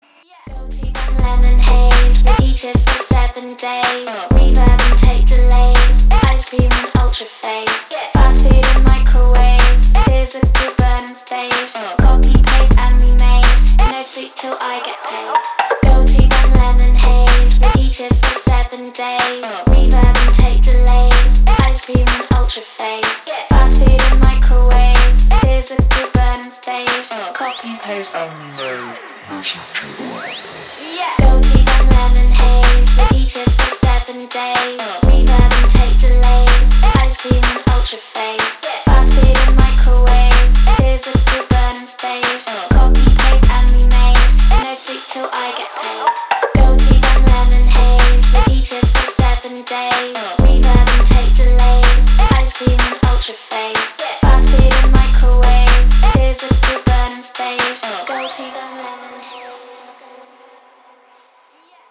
• Качество: 320, Stereo
женский вокал
атмосферные
dance
Tech House
Bass
UK garage
Стиль: House